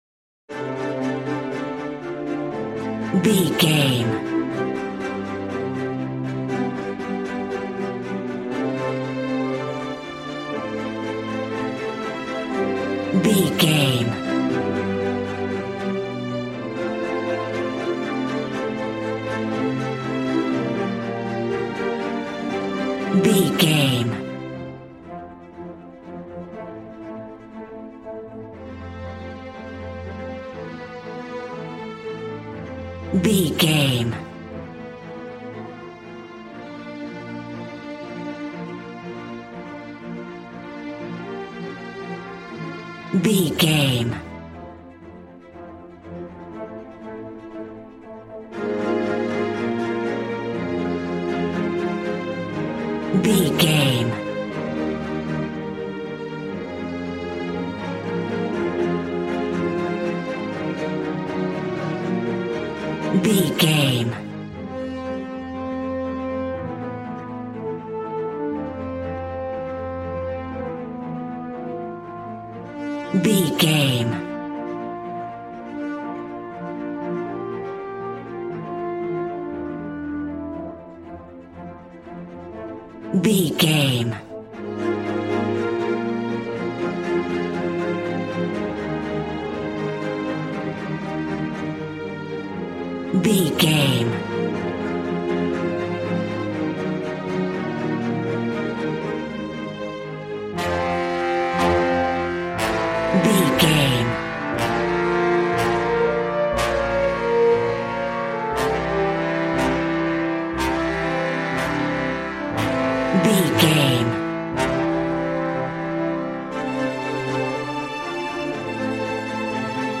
A classical music mood from the orchestra.
Regal and romantic, a classy piece of classical music.
Ionian/Major
cello
violin
strings